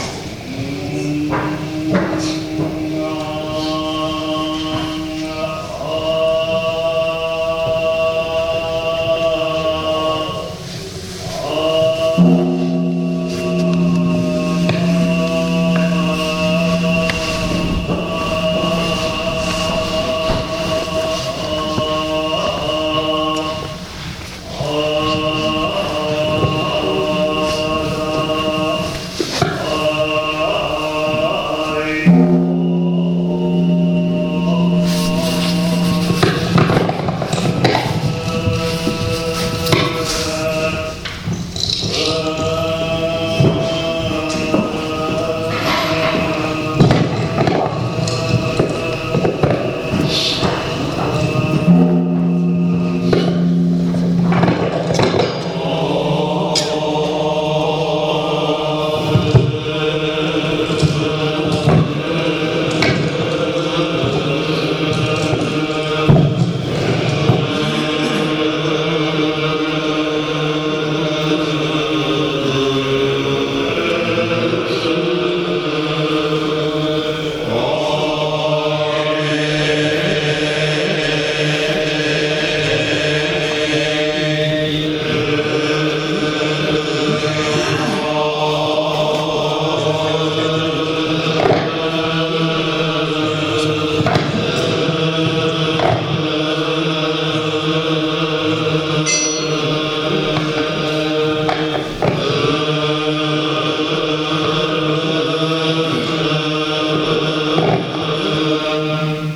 唱えられる理趣経は、普通唱えられる理趣経とは異なった独特の節まわし。
金堂の奥のほうから静かにお経が聞こえてくる。
・白襲（しろがさね）に身を包んだ若い僧侶が唱える長音理趣経の頭に続き、山内寺の住職が勤める職衆が理趣経に節を付けたお経を唱えながら、伽藍金堂の中を回り続けます。
金堂の中の板敷を廻りながら行道は行われる。 ◆祈りのお経 始め経（長音理趣経） ～ 理趣経（繰り返し） ～ 終わり経 ◆祈りの聲・経のひとしずく 始め経（長音理趣経） 前日の続きから静かに不断経は始まる。